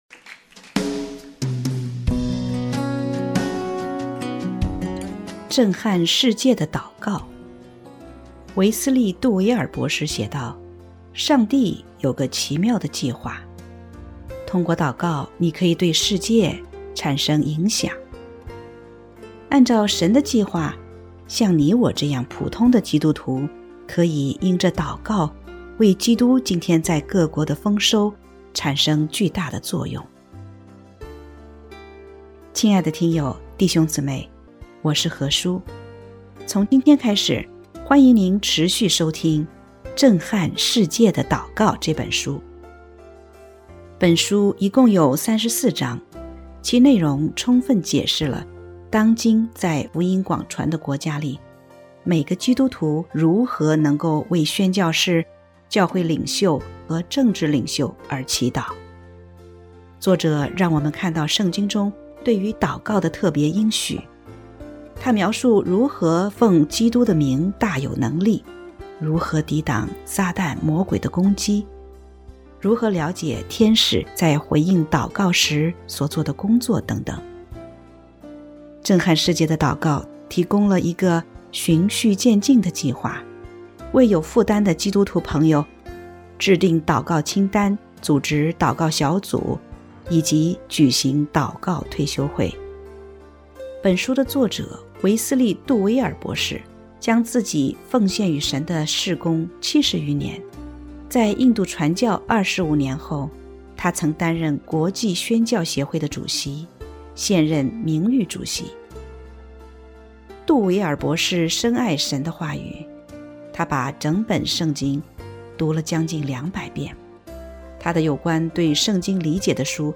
首页 > 有声书 | 灵性生活 | 震撼世界的祷告 > 震撼世界的祷告 第一章：神呼召你祷告